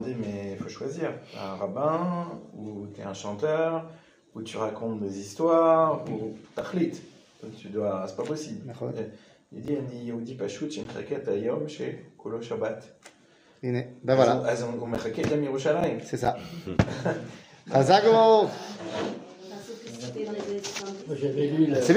שיעור מ 15 מאי 2023 56MIN הורדה בקובץ אודיו MP3